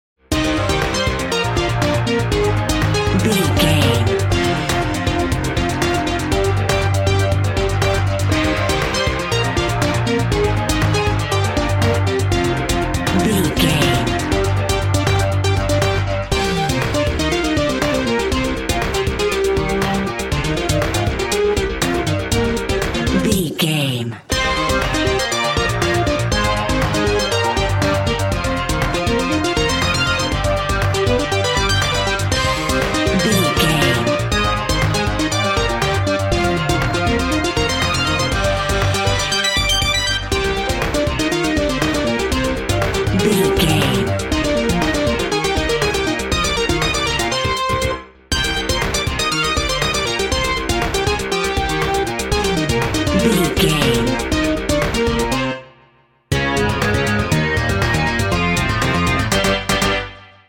Ionian/Major
bouncy
bright
cheerful/happy
funky
groovy
lively
playful
uplifting
synthesiser
drum machine